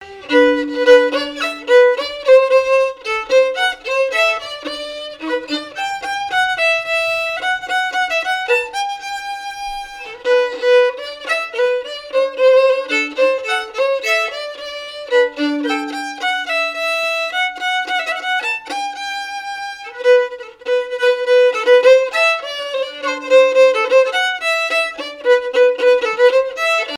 Mémoires et Patrimoines vivants - RaddO est une base de données d'archives iconographiques et sonores.
danse : polka
violoneux
Pièce musicale inédite